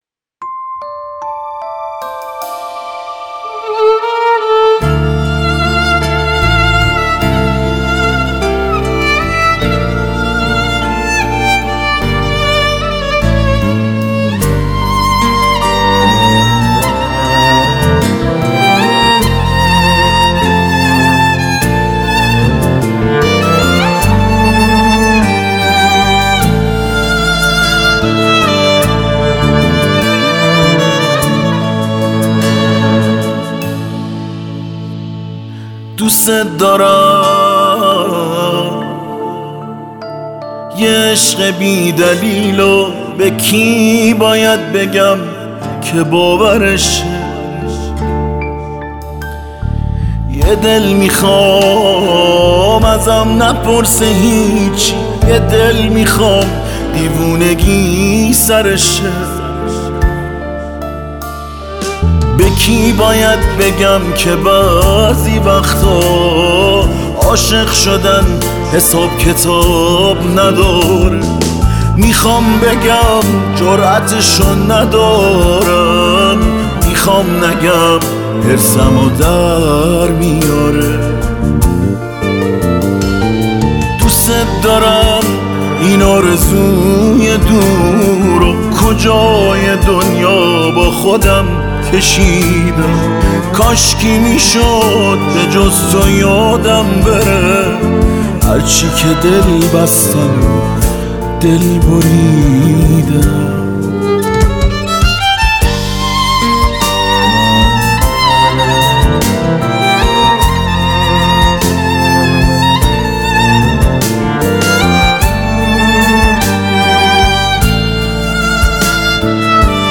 آهنگ احساسی برای مادر از دست رفته